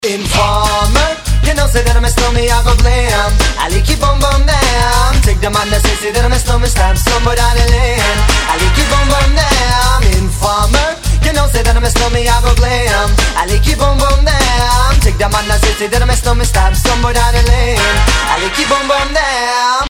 • Качество: 320, Stereo
Хип-хоп